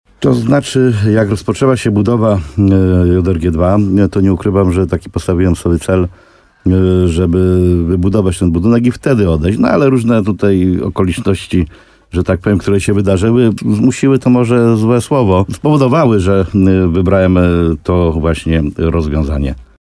Jak podkreślił w programie Słowo za słowo na antenie RDN Nowy Sącz, termin zdania służby jest nieco wcześniejszy niż pierwotnie planował.